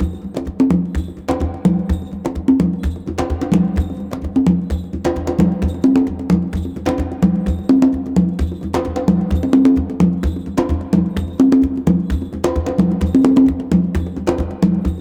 CONGABEAT3-L.wav